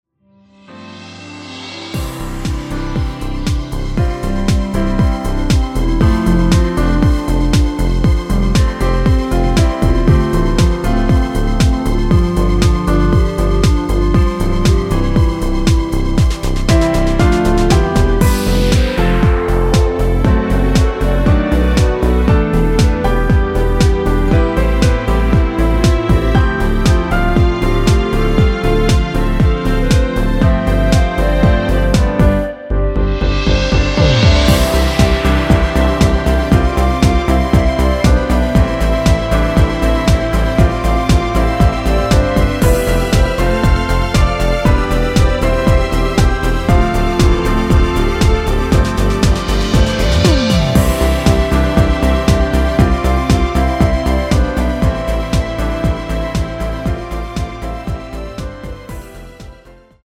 전주가 없는 곡이라 2마디 전주 만들어 놓았습니다. 랩부분과 마지막 랩부분은 후렴부분도 없습니다.
(랩 삭제 -2)키 멜로디 포함된 MR 입니다.(미리듣기 참조)
◈ 곡명 옆 (-1)은 반음 내림, (+1)은 반음 올림 입니다.
앞부분30초, 뒷부분30초씩 편집해서 올려 드리고 있습니다.